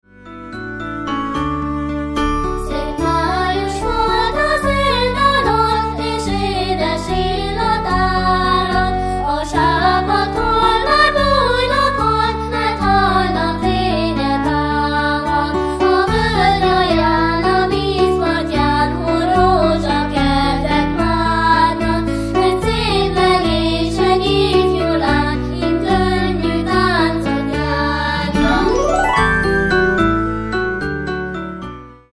kisiskolások adják elő.